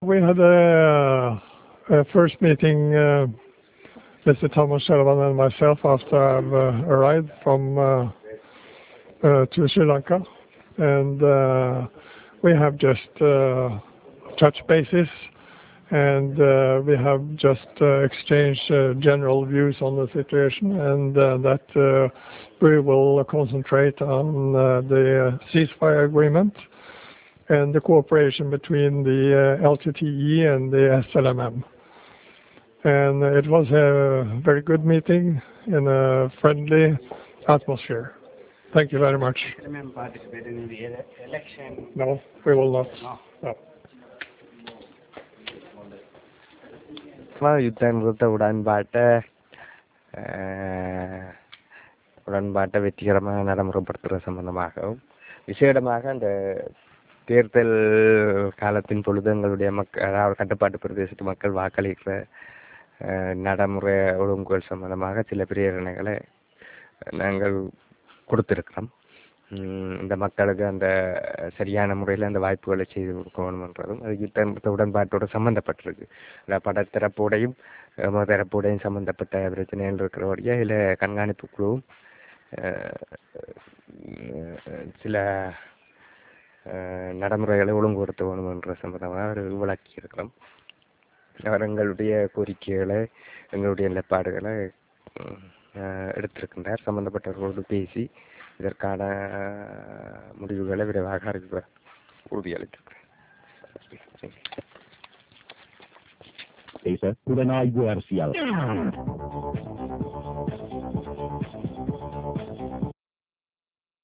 Real Audio Icon Maj. Gen. Furuhovde (English),
Thamilchelvan (Tamil) talk to journalists